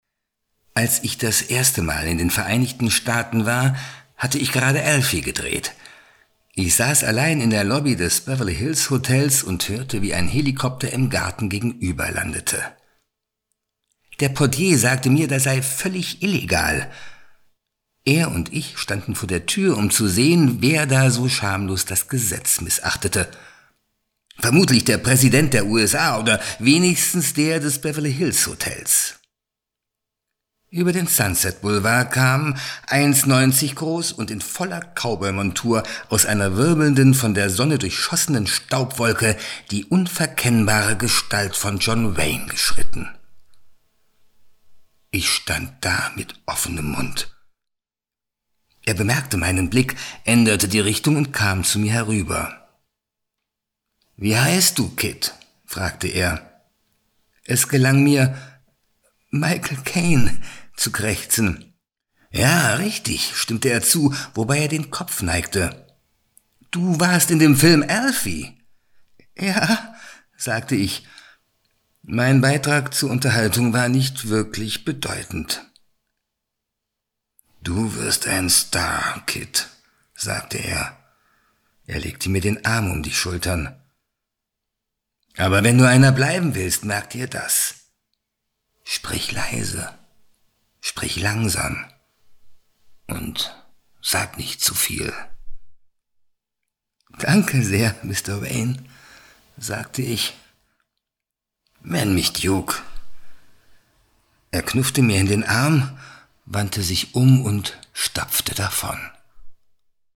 Sorry, Dein Browser unterstüzt kein HTML5 Michael Caine - Die verdammten Türen sprengen Das Örtliche - Das Örtliche-Hellsehen Postbank - Commercial